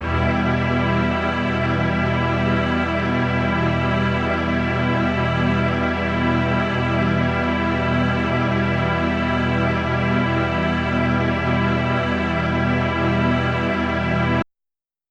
SO_KTron-Ensemble-Emin.wav